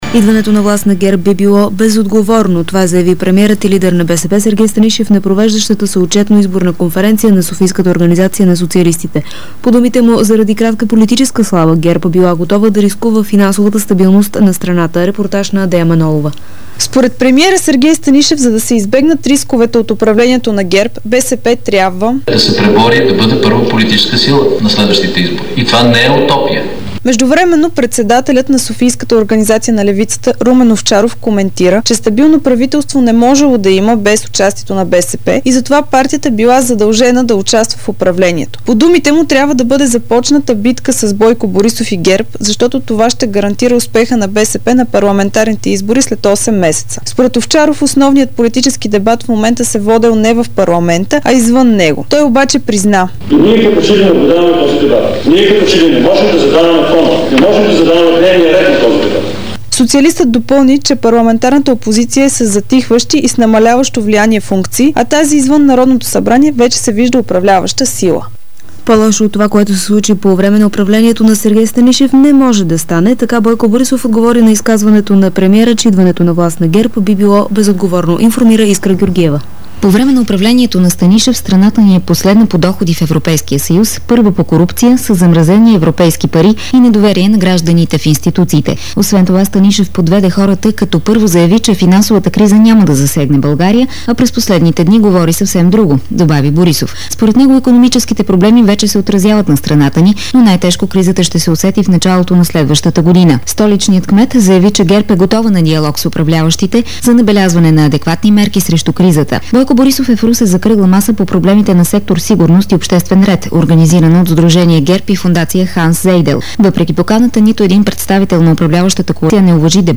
Обзорна информационна емисия - 15.11.2008